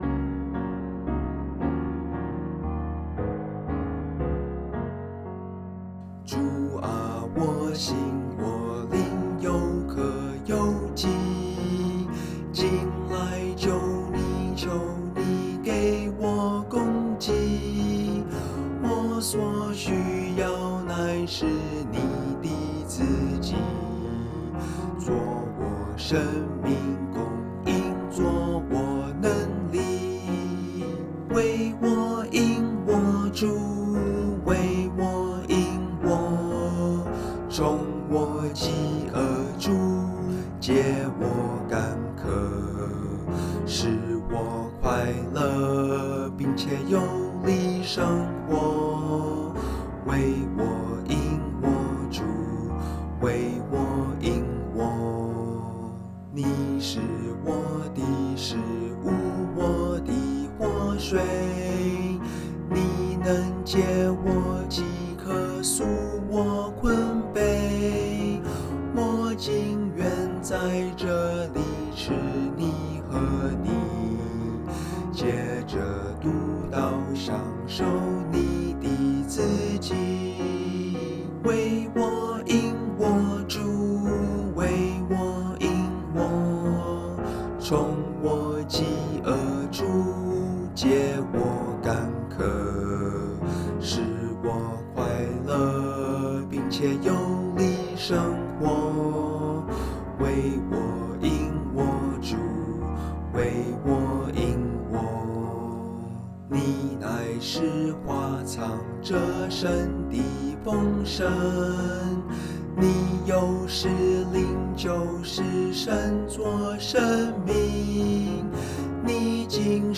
Bb Major